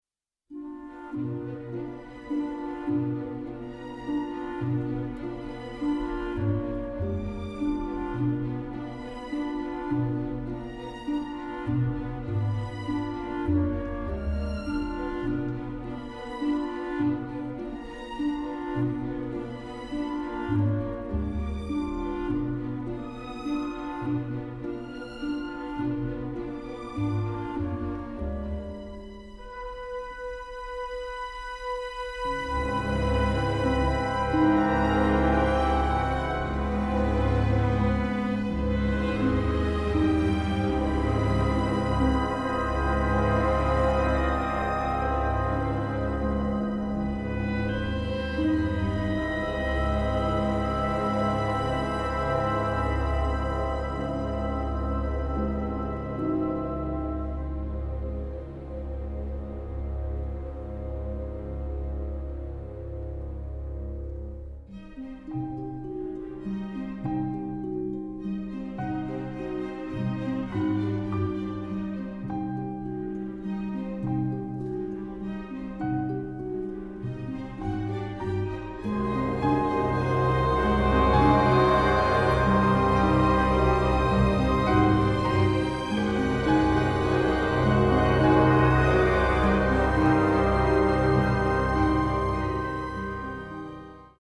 an energetic, dark and crude orchestral score